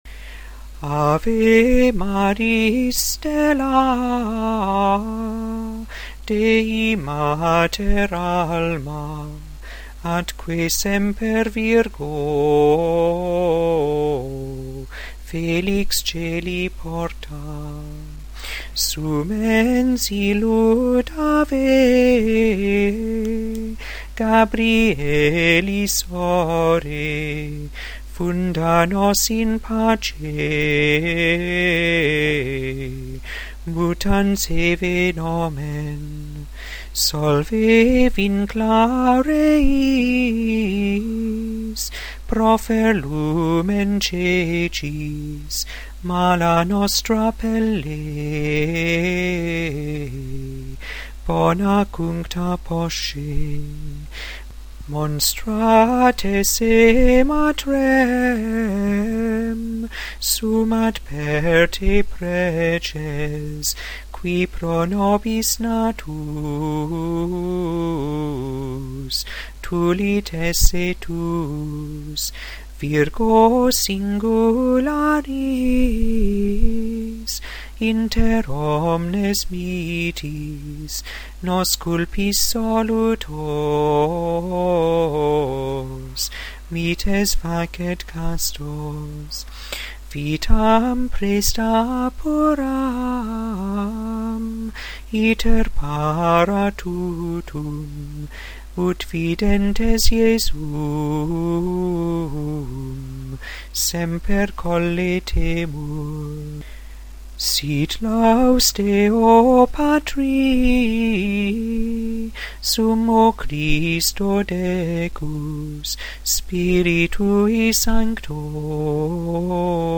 2. Gregorian Chant